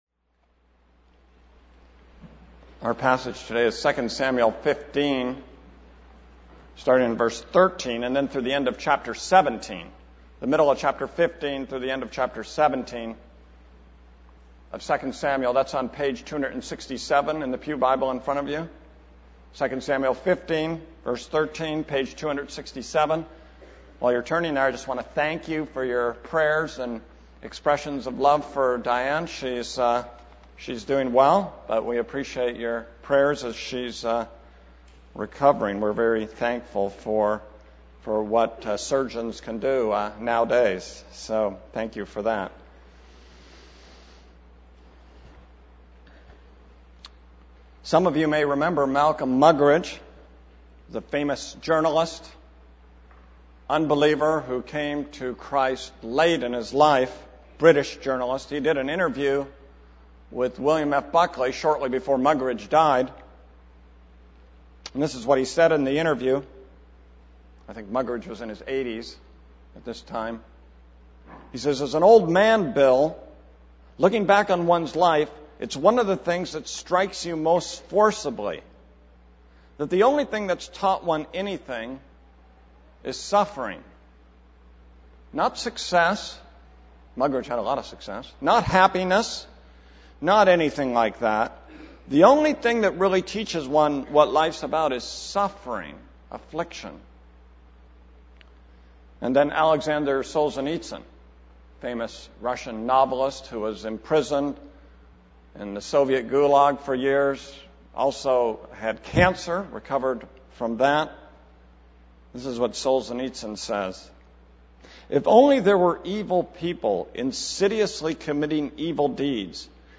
This is a sermon on 2 Samuel 15:13-17:29.